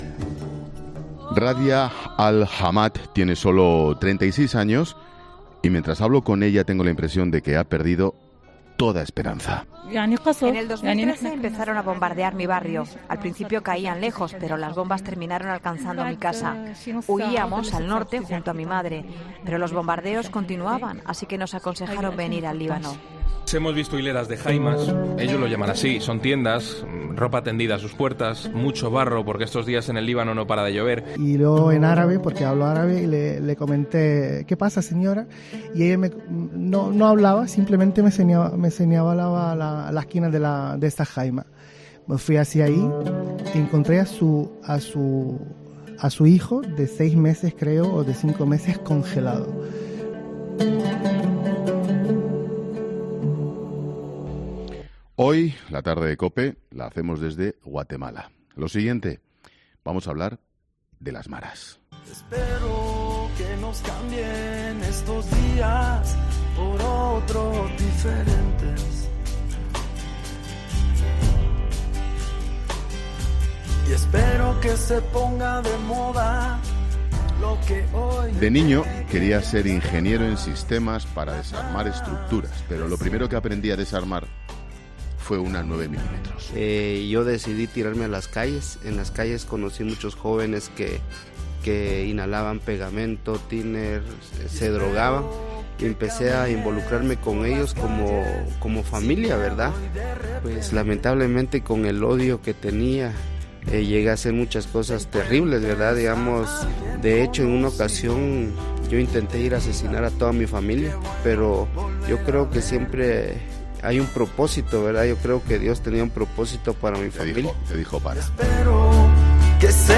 Rincones a los que acceden simplemente con una mochila y un micrófono bajo el brazo.